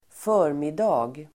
Uttal: [²f'ö:rmida:g el.-mid:a]